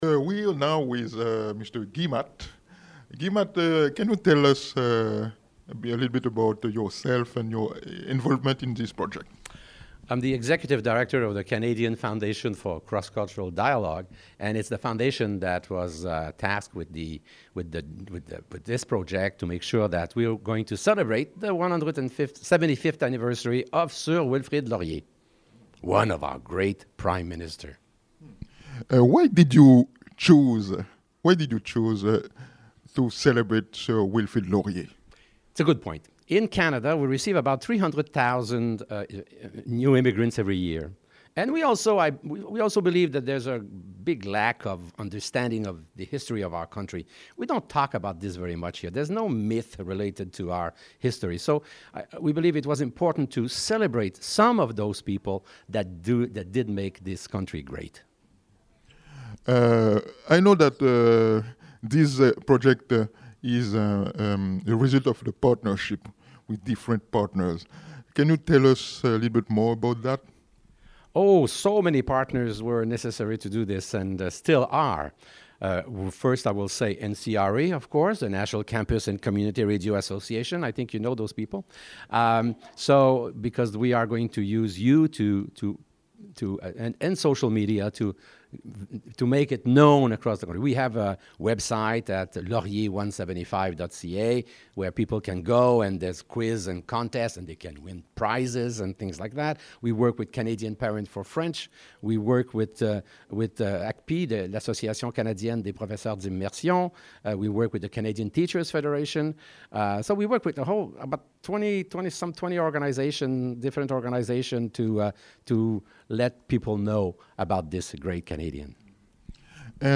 Recording Location: Ottawa
Type: Interview
320kbps Stereo